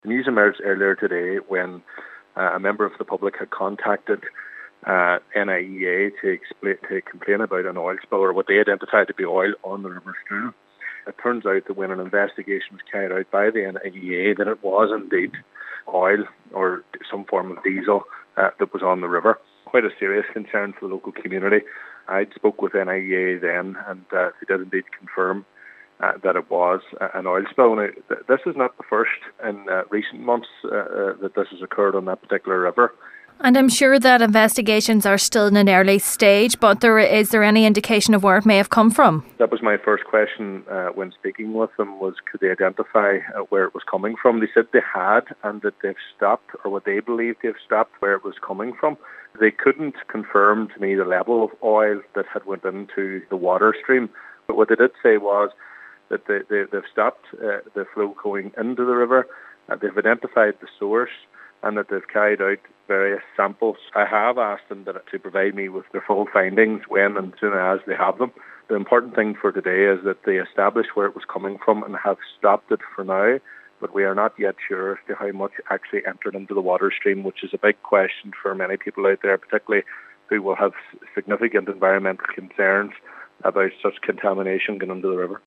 West Tyrone MLA Daniel McCrossan says there is much concern as to the extent of the environmental damage caused: